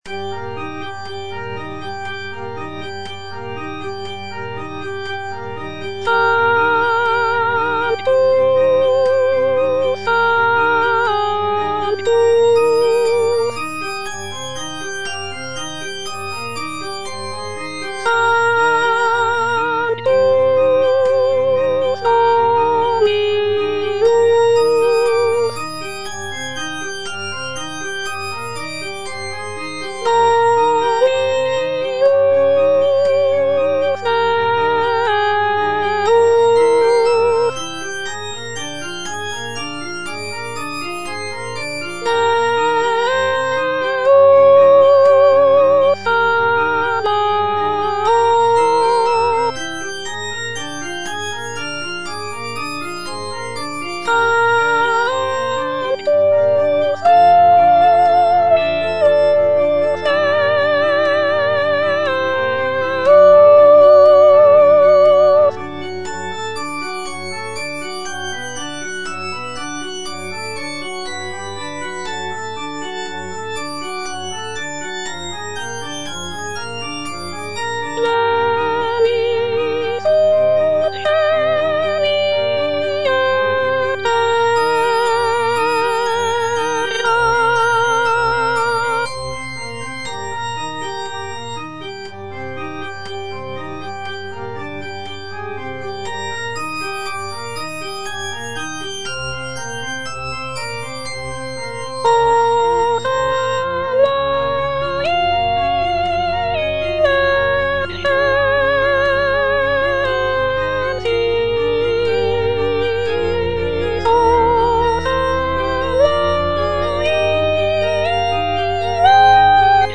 G. FAURÉ - REQUIEM OP.48 (VERSION WITH A SMALLER ORCHESTRA) Sanctus - Soprano (Voice with metronome) Ads stop: